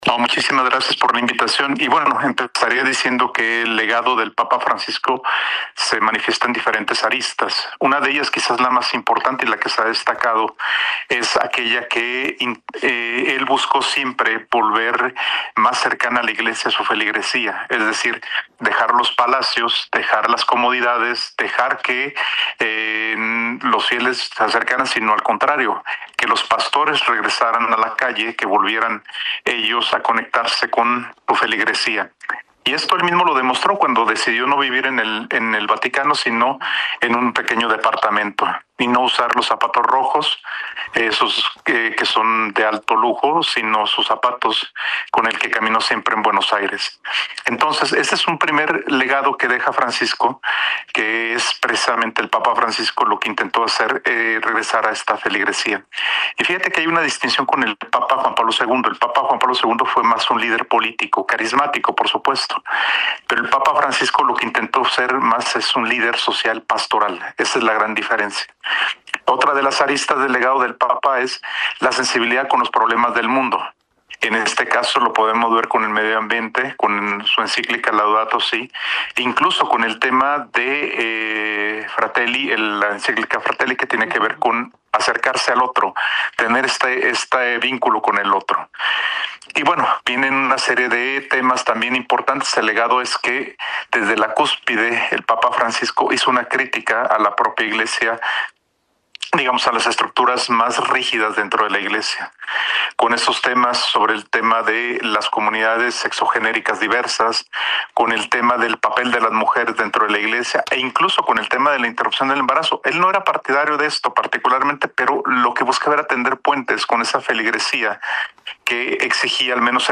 15-ENTREV-PAPA-LA-SALLE.mp3